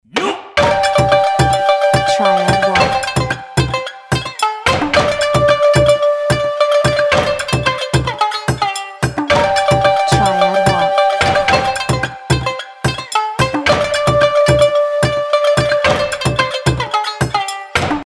Lively Japanese-style track with shakuhachi and shamisen